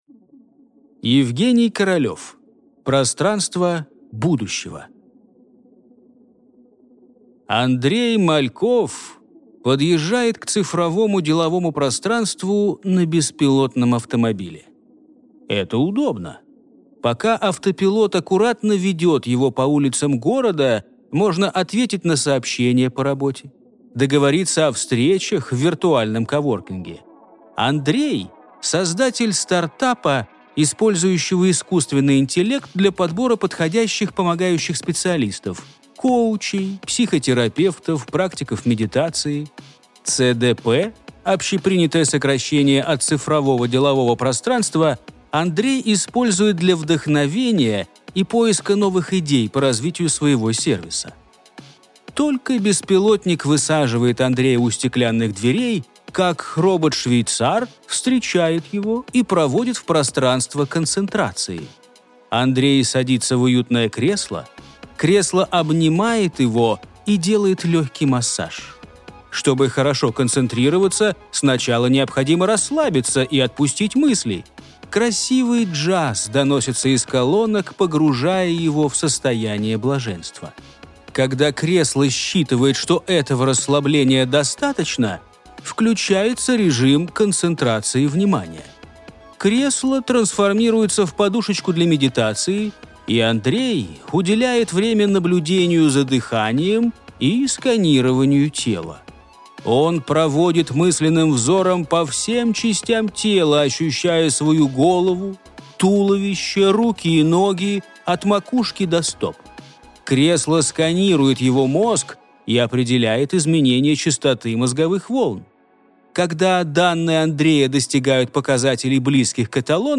Аудиокнига Пространство будущего | Библиотека аудиокниг